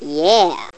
yeah.mp3